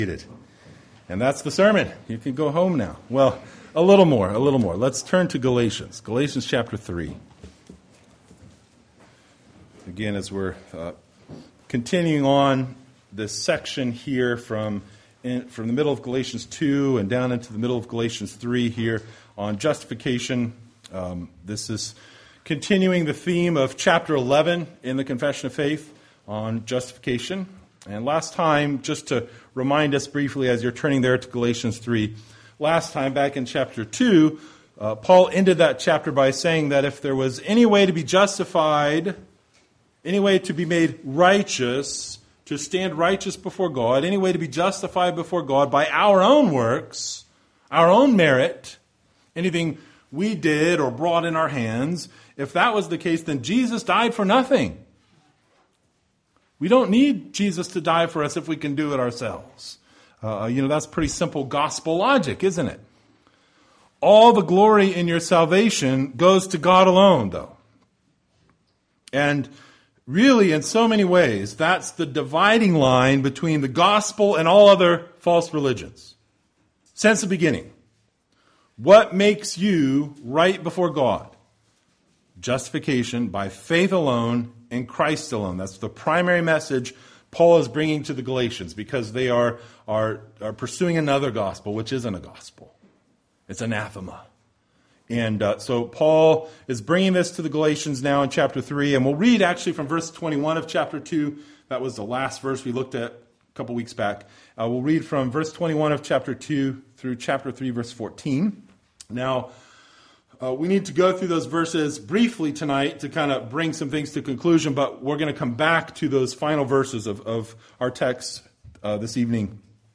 Galatians 3:1-14 Service Type: Sunday Evening Related « Sacrifice Of Justification